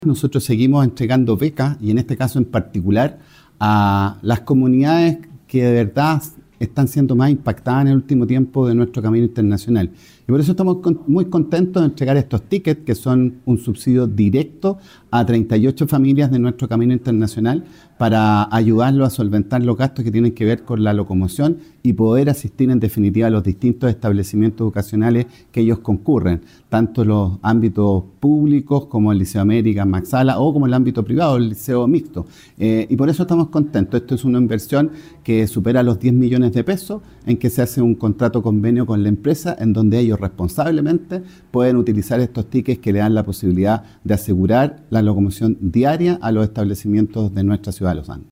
Rivera explicó en qué consiste el beneficio.